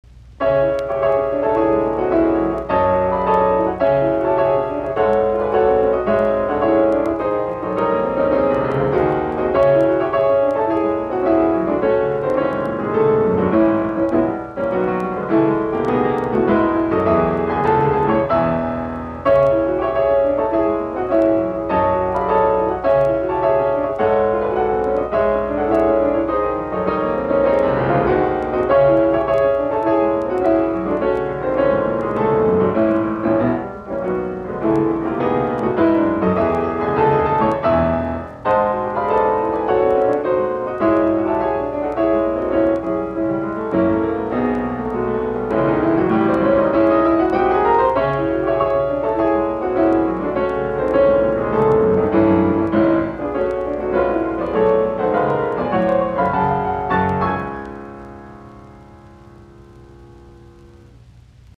Soitinnus: Piano.